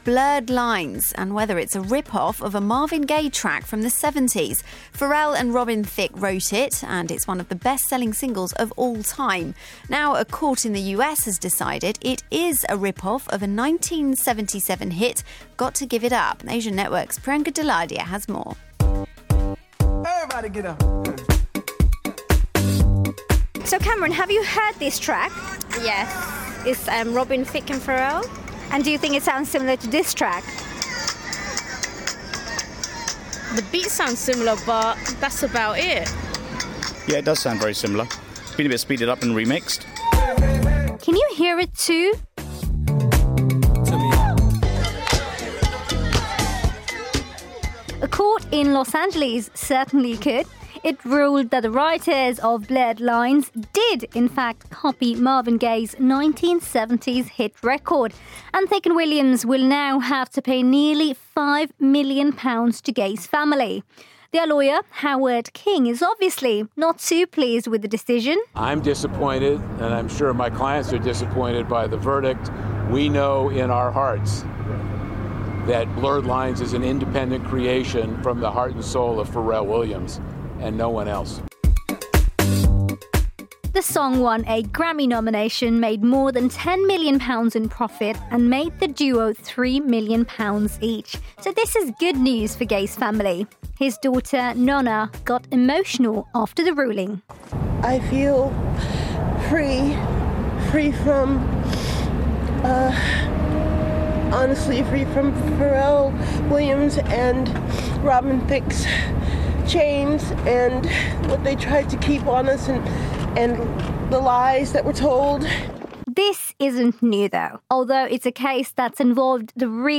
A court in the US has ruled that the writers of Blurred Lines - Pharrell Williams and Robin Thicke - copied a Marvin Gaye track. Report for BBC Asian Network.